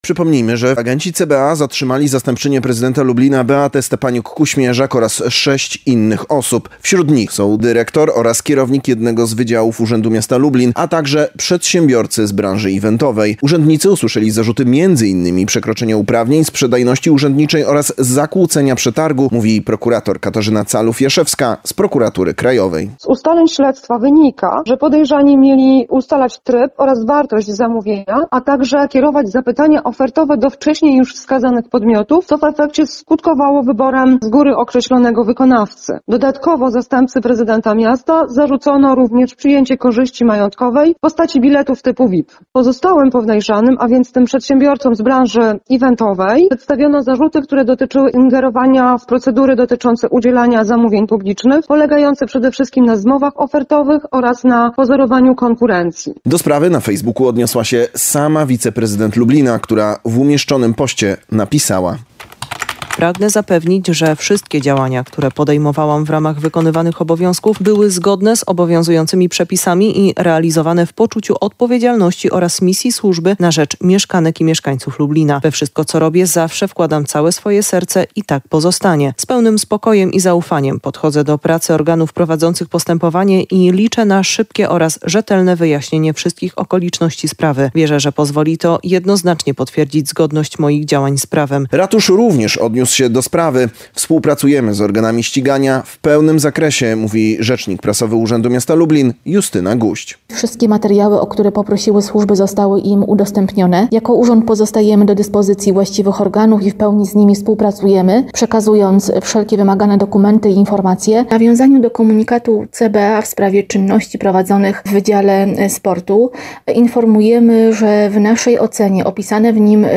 W sprawie głos zabierają przedstawiciele instytucji kultury, a także prezydent Lublina Krzysztof Żuk i sama podejrzana.